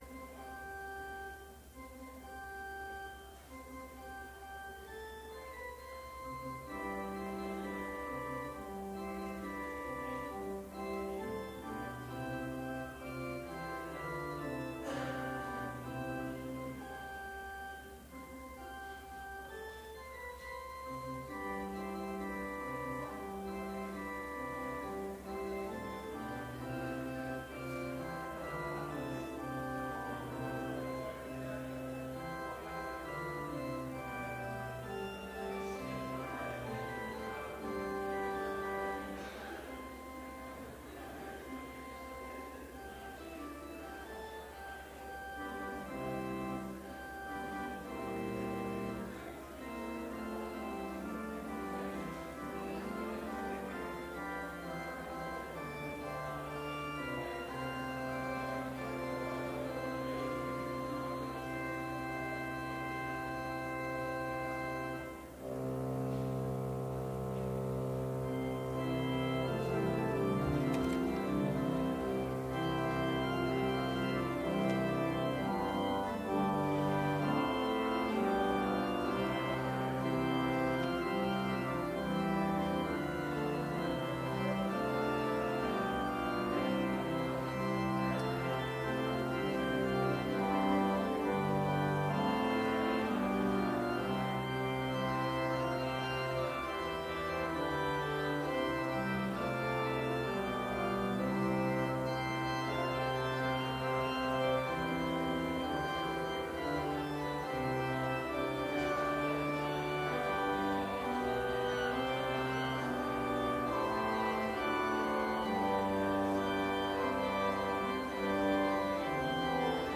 Complete service audio for Chapel - December 6, 2018
Hymn 280 - Ride On, Ride On in Majesty
Devotion Prayer Hymn 277 - All Glory, Laud, and Honor View vv. 1